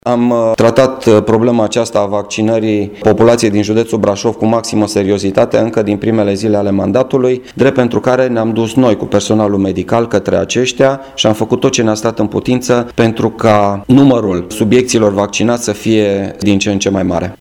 La informarea de la Prefectura Braşov a participat şi prefectul Marian Rasaliu